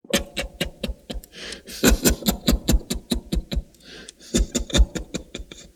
burer_idle_3.ogg